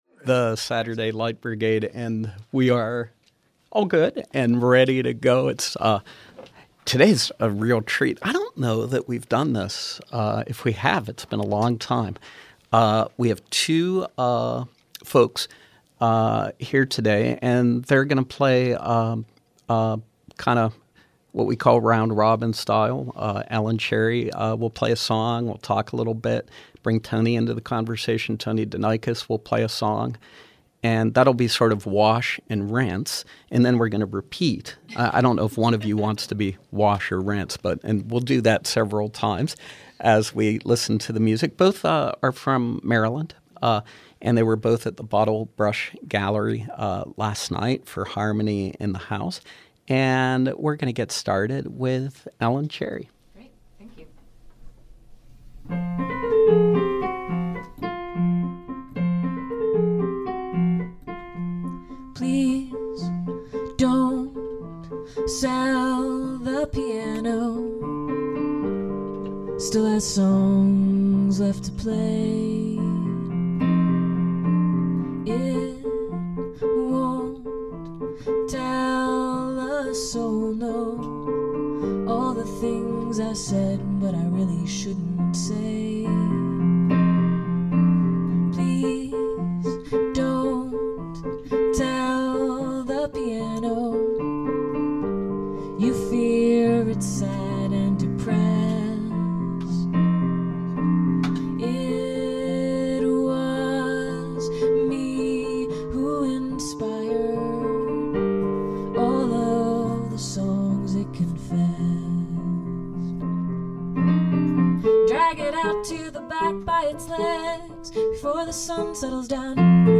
performing live